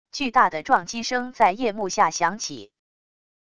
巨大的撞击声在夜幕下响起wav音频